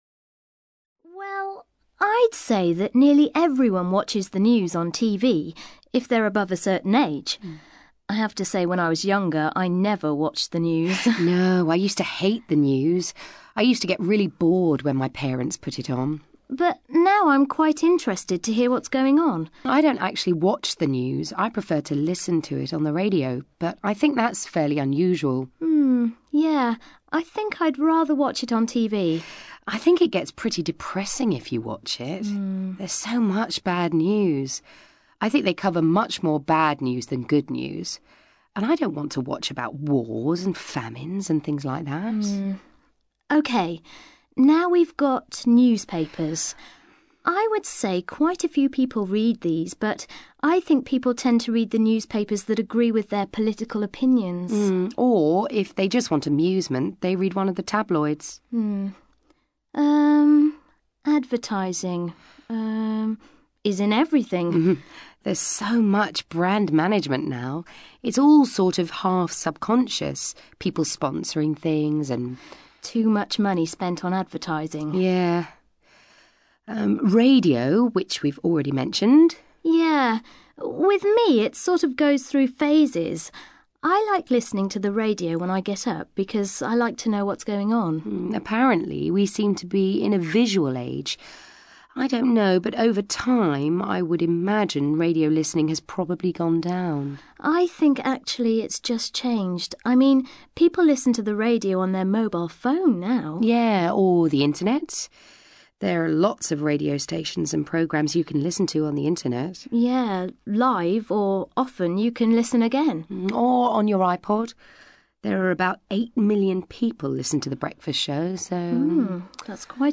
Speaking Test: Practice
You will listen to two candidates during the Speaking Test.